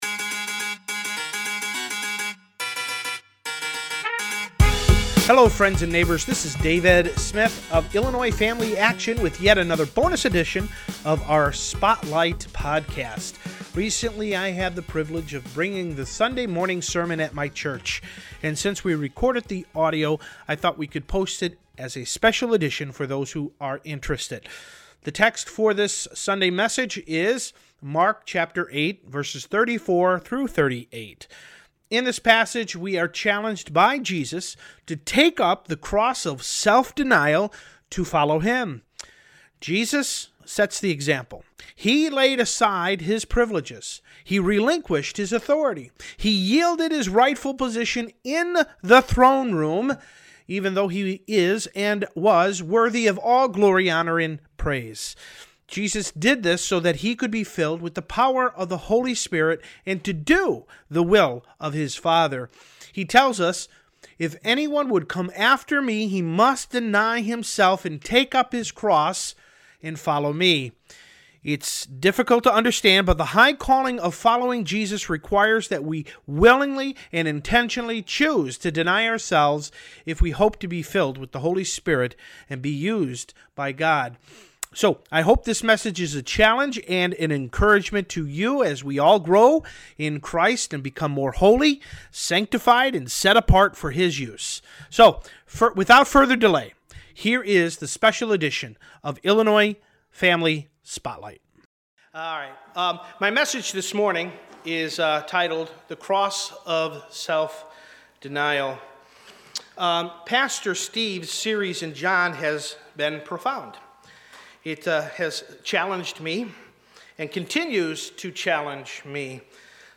sermon-bonus.mp3